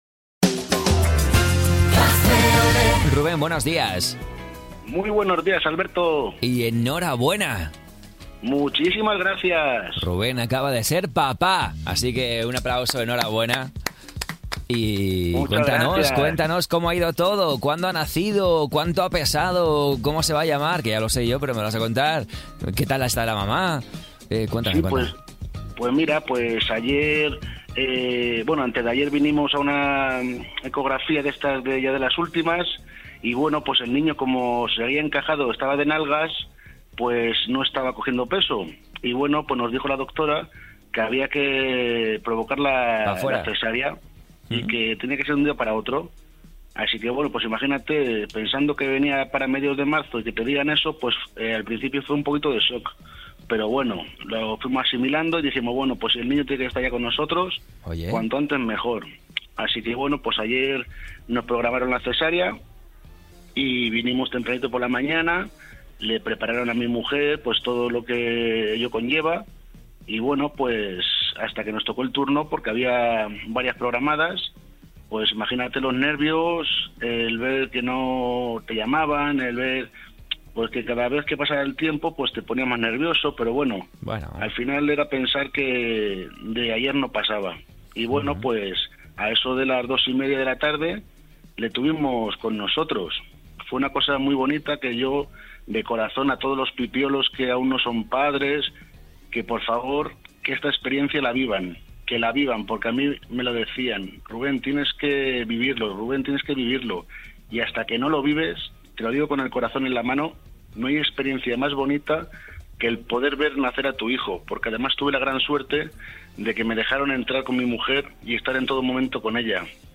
El programa despertador de Radiolé